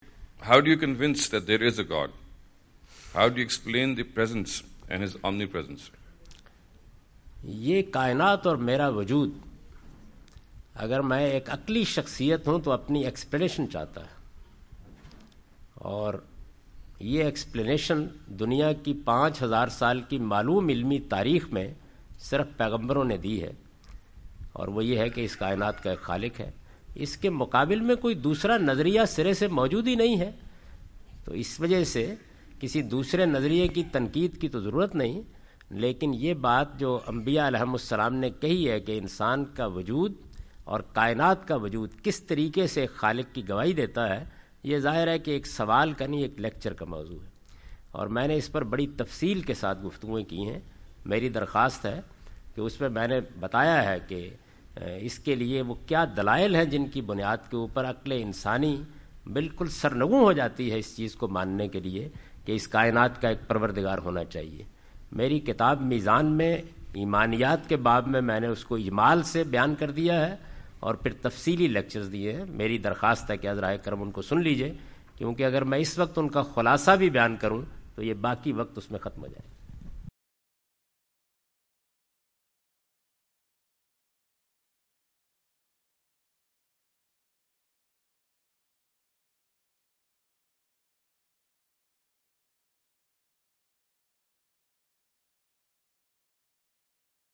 Category: English Subtitled / Questions_Answers /
Javed Ahmad Ghamidi answer the question about "Explaining Existence and Omnipresence of God" during his US visit.
جاوید احمد غامدی اپنے دورہ امریکہ کے دوران ڈیلس۔ ٹیکساس میں "خدا کا وجود اور واحدانیت" سے متعلق ایک سوال کا جواب دے رہے ہیں۔